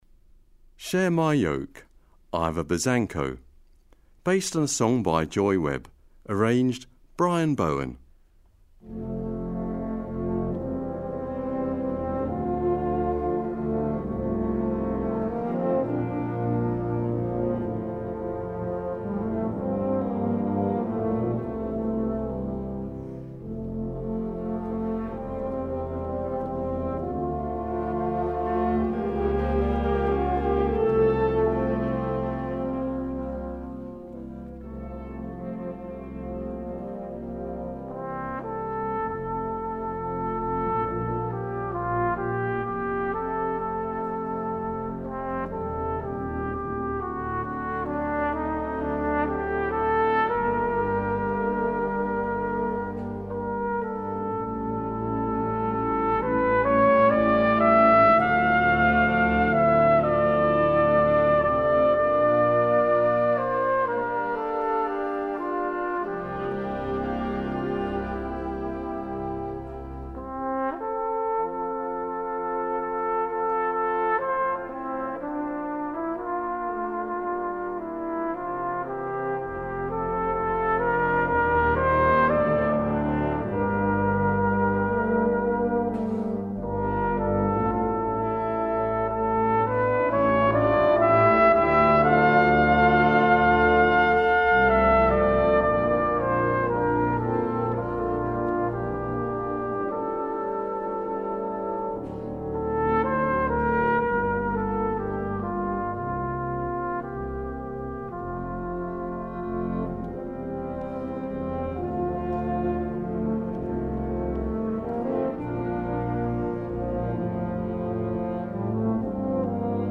Gattung: Solo für Flügelhorn und Blasorchester
Besetzung: Blasorchester